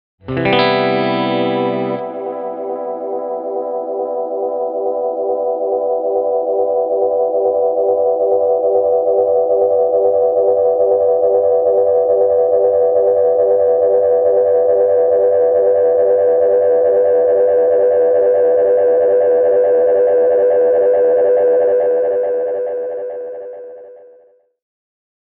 Tältä Flashback X4:n efektityypit kuulostavat: